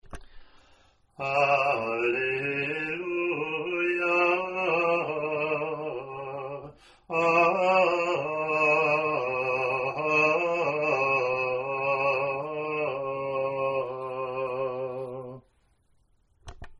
Alleluia Acclamation 1